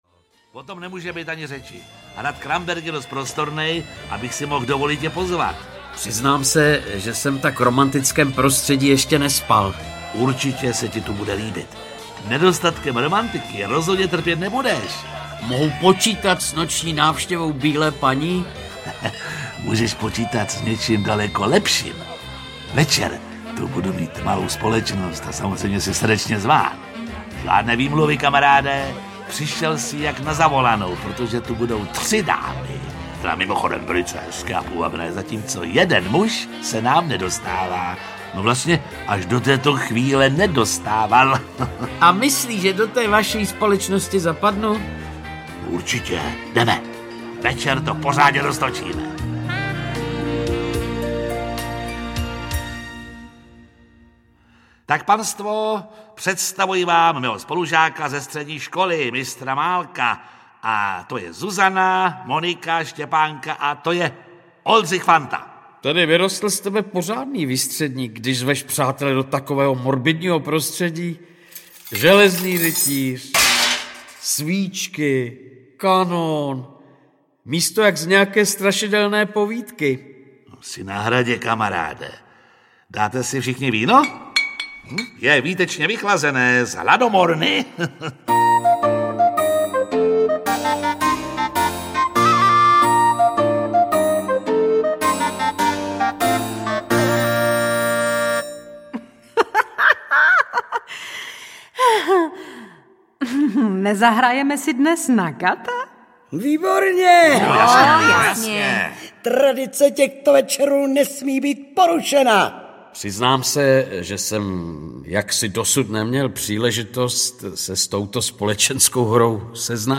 Populární čeští herci čtou nejslavnější české hororové povídky!
Ukázka z knihy
• InterpretTereza Bebarová, Bohumil Klepl, Jiří Lábus, Norbert Lichý, Zdeněk Mahdal, Michaela Maurerová, Valérie Zawadská